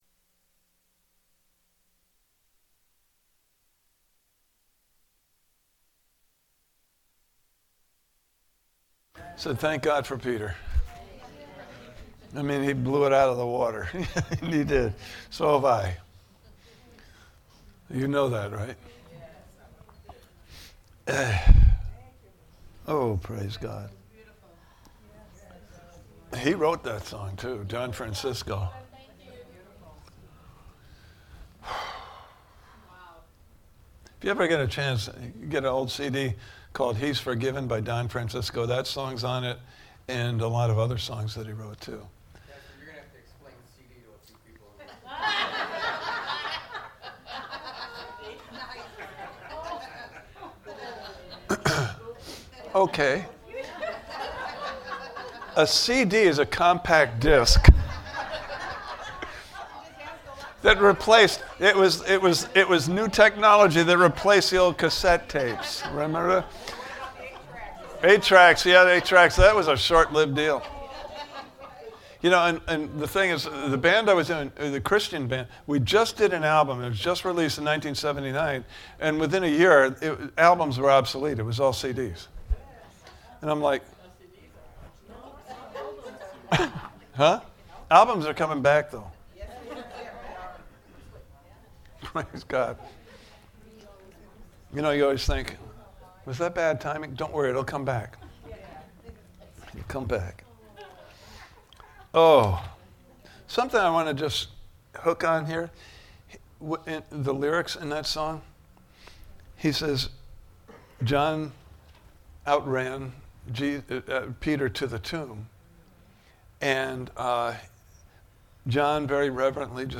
Living a Redeemed Life Service Type: Sunday Morning Service « Part 2